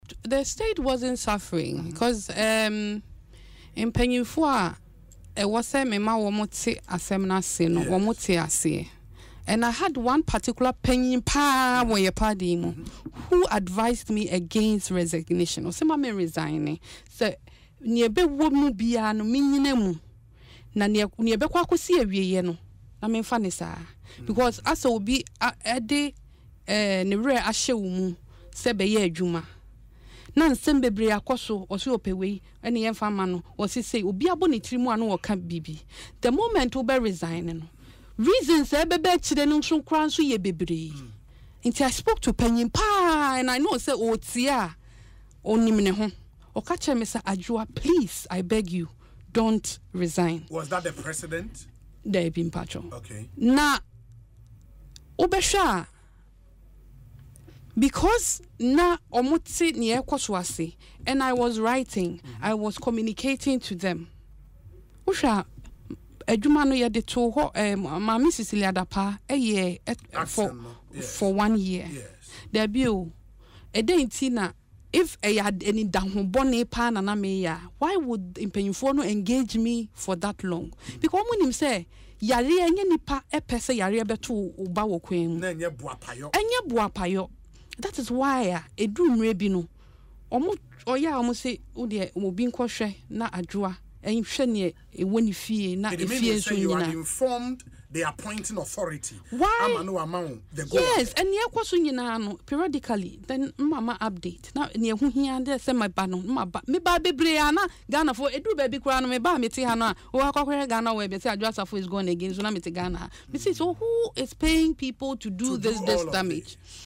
Speaking on Asempa FM’s Ekosii Sen, Adwoa Safo revealed that the NPP stalwart, whom she did not name, encouraged her to stay on despite public criticism, assuring her that the appointing authority and all relevant stakeholders were aware of her situation.